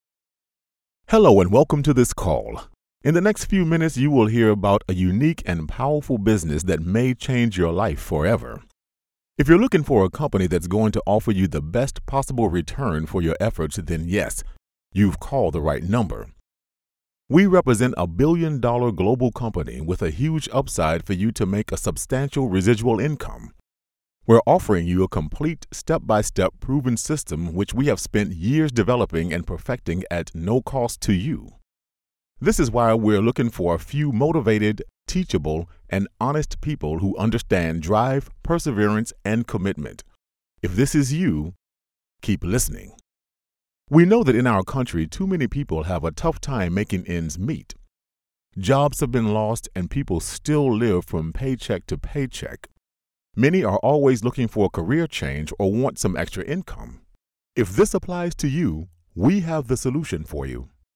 Male
Adult (30-50), Older Sound (50+)
Phone Greetings / On Hold